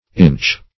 Inch \Inch\ ([i^]nch), n. [Gael. inis.]